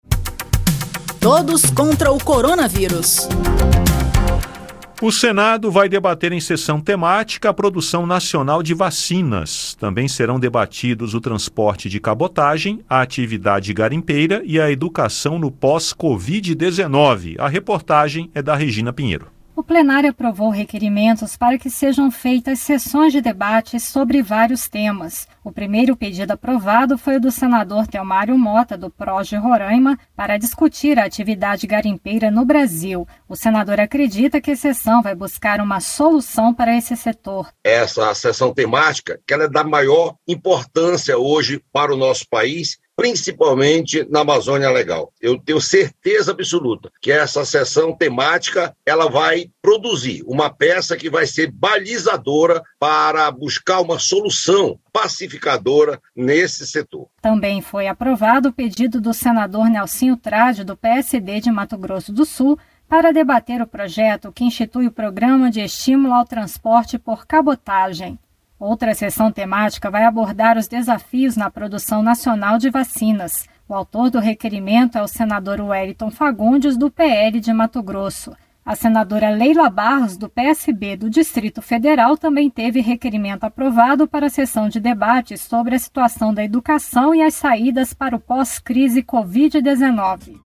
O Plenário aprovou requerimentos para que sejam feitas Sessões de Debates sobre vários temas. Entre eles, o Senado irá debater a produção nacional de vacinas, e outra sessão discutirá o transporte de cabotagem, a atividade garimpeira e a educação no pós-pandemia. Ouça detalhes na reportagem